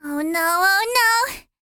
Worms speechbanks
hurry.wav